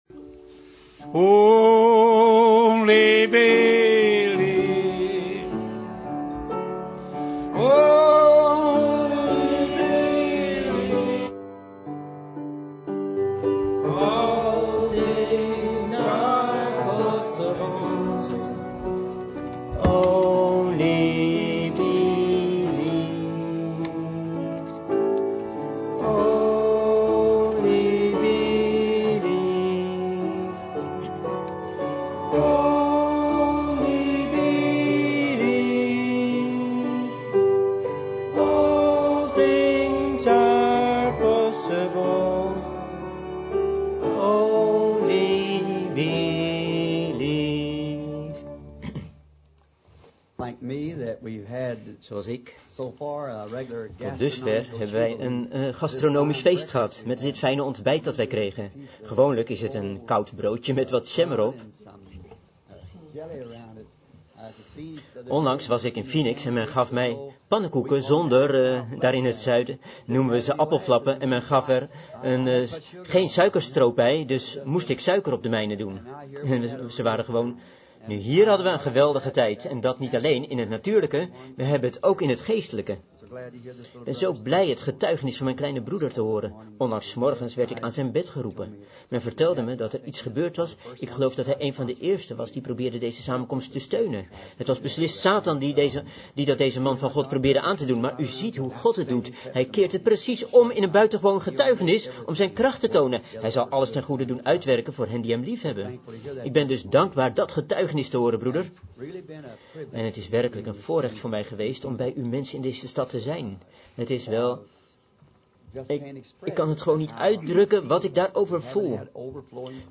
De vertaalde prediking "Who is Jesus?"
gehouden in Holiday inn, Topeka, Kansas, USA